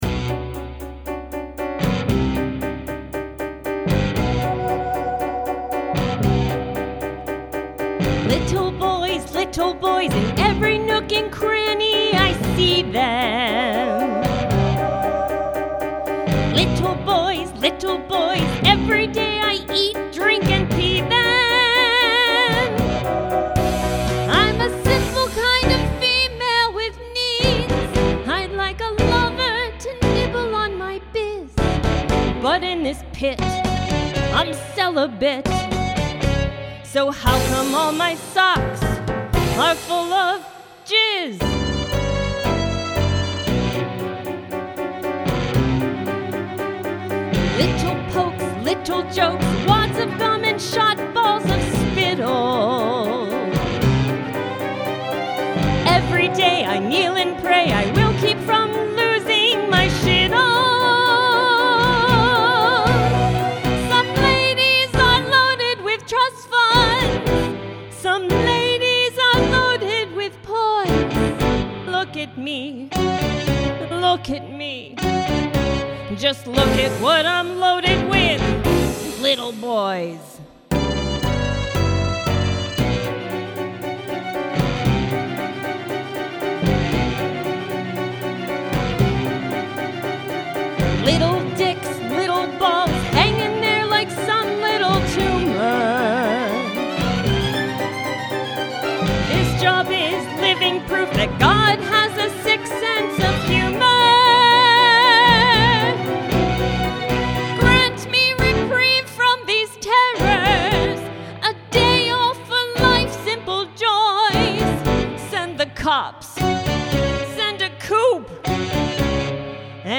Song Demos
(Music; Demo Song Production and Accompaniment)